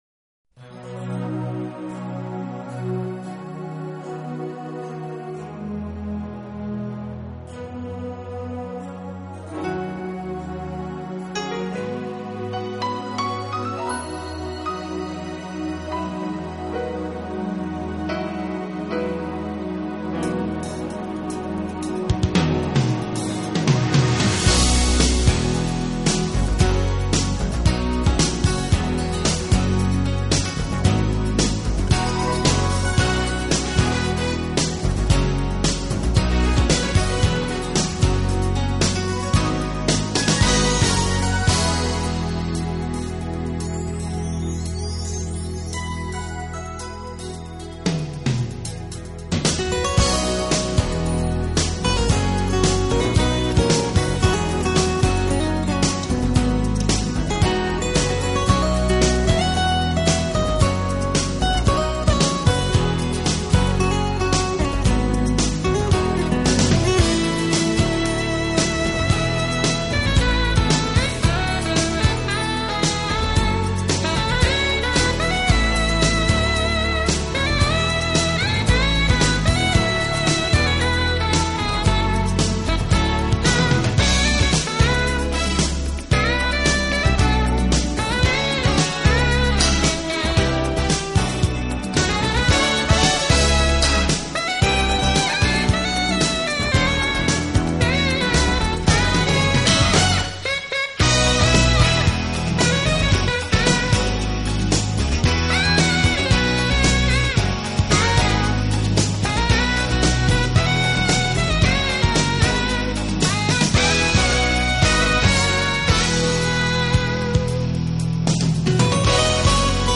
【浪漫爵士】